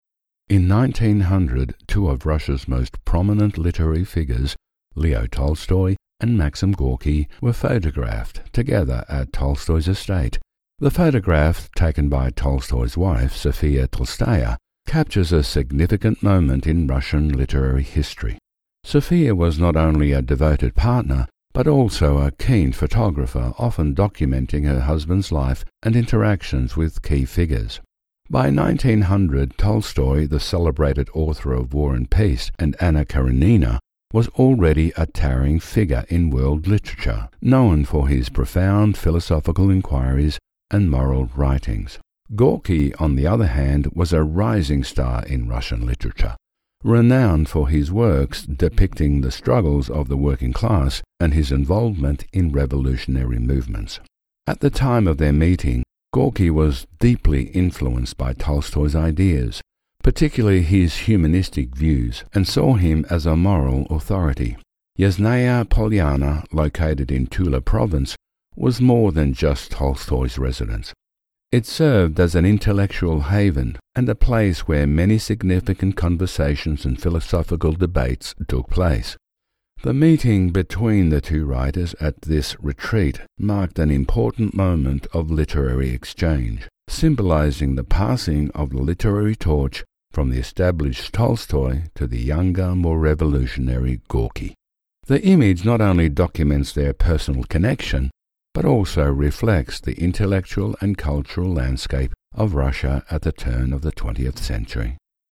Male
English (Australian)
Older Sound (50+)
Documentary
Words that describe my voice are Warm, Corporate, Authoritative.